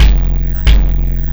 Kick Particle 06.wav